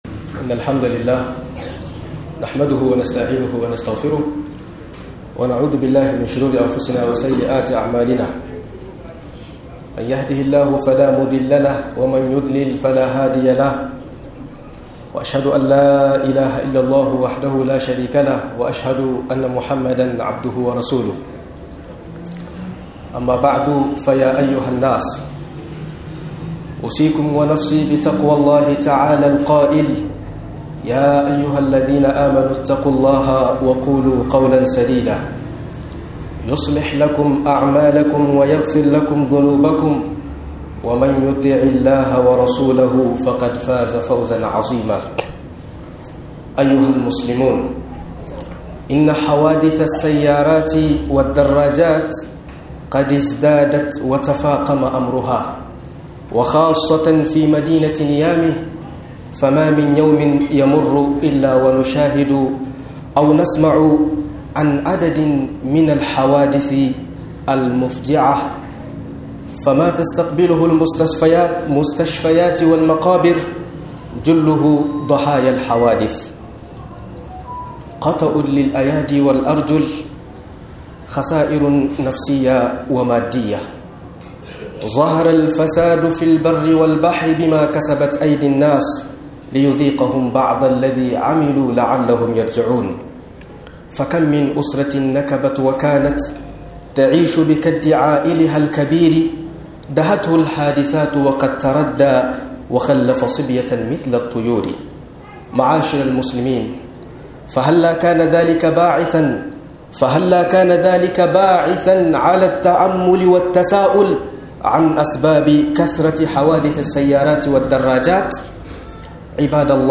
Accident Abubwan da ke janyo yawan Accident - MUHADARA